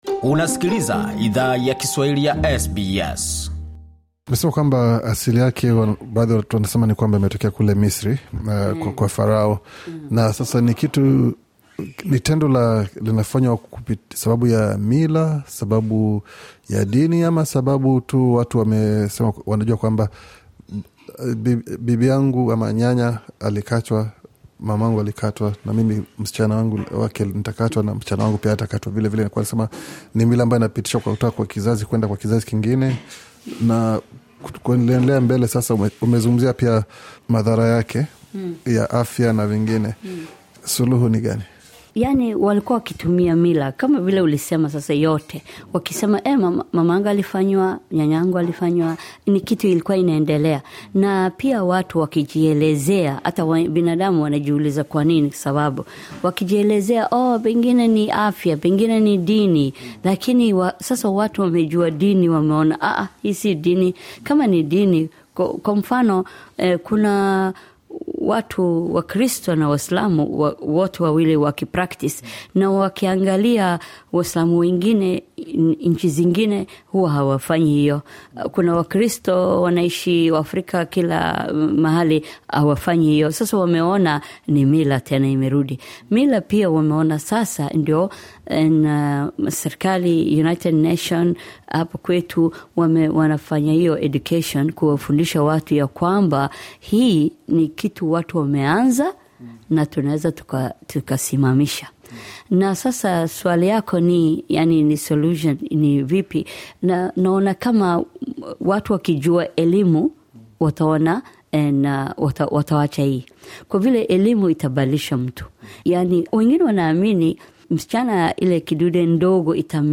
Katika mahojiano maalum kuhusu hoja hii, alifunguka kuhusu mbinu za kutokomeza ukeketaji.